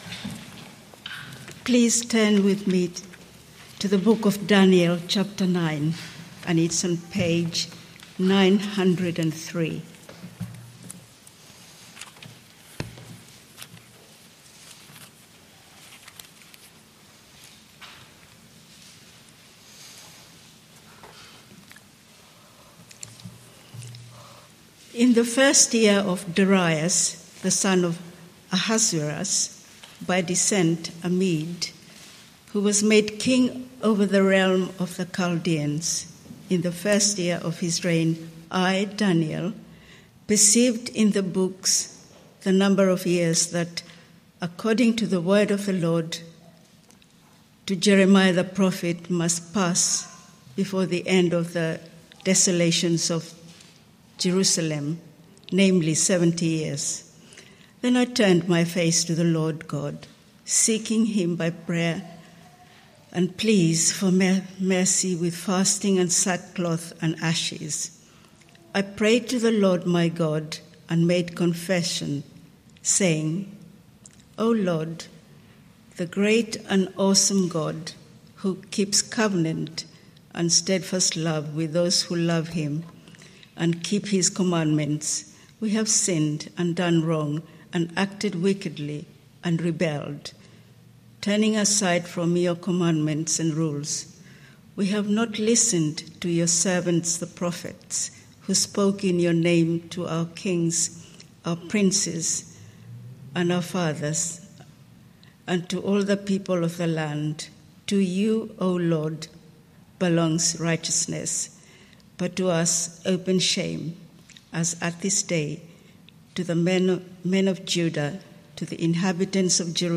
PM Theme: Sermon Search media library...